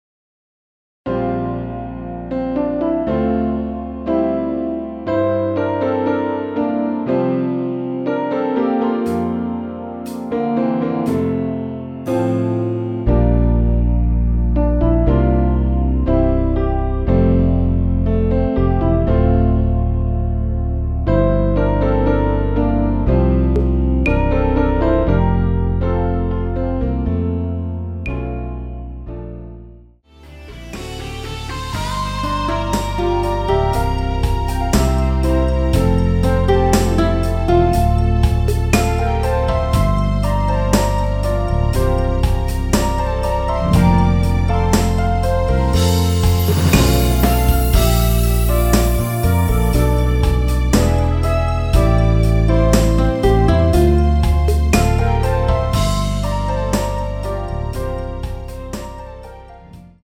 전주없이 노래가 바로 시작 되는 곡이라서 전주 만들어 놓았습니다.
9초부터 하이햇 소리 4박째 노래 시작 하시면 됩니다.
앞부분30초, 뒷부분30초씩 편집해서 올려 드리고 있습니다.